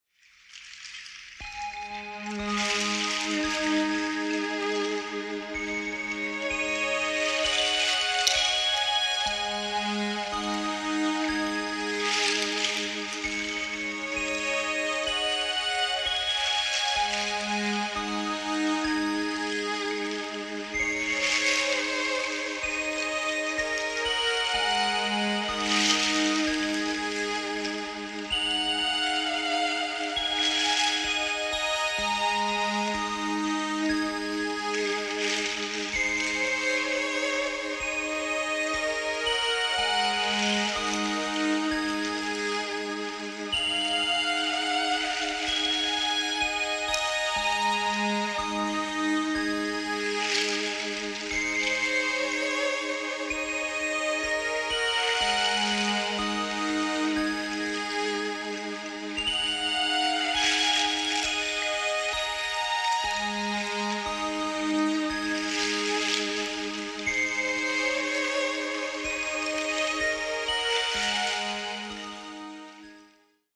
Mexican guitarist and ambient artist
This expansive interplanetary soliloquy is undoubte